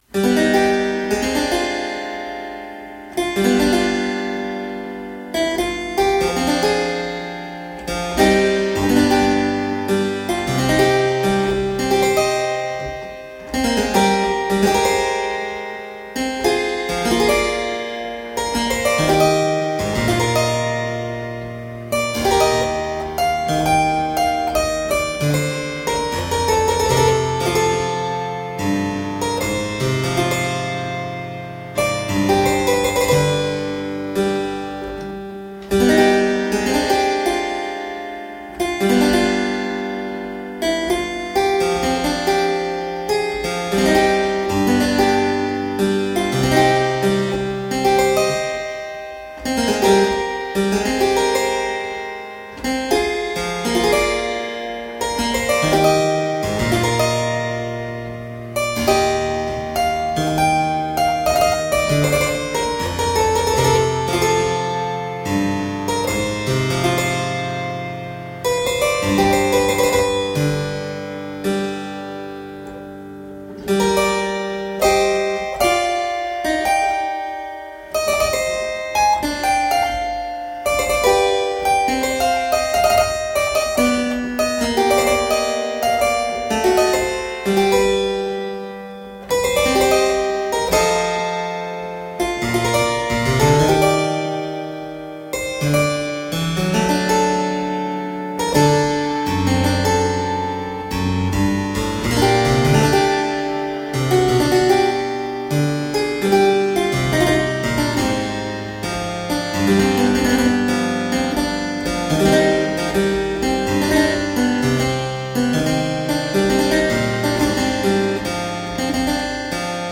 Solo harpsichord music.
played on a wonderful original French harpsichord of 1661